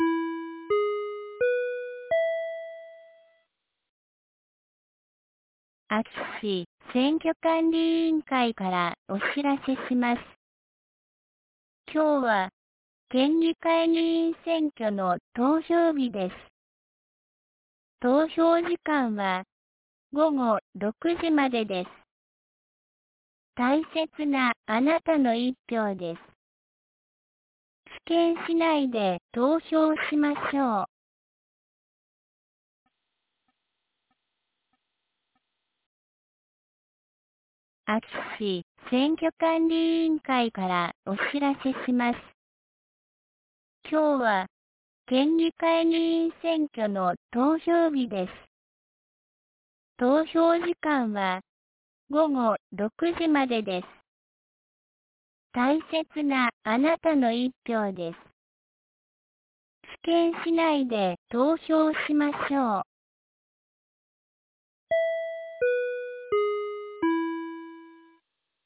2023年04月09日 10時01分に、安芸市より伊尾木、下山、川北、江川、奈比賀、入河内、土居、僧津、井ノ口、栃ノ木、下尾川、穴内、赤野へ放送がありました。